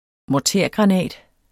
Udtale [ mɒˈteˀɐ̯- ]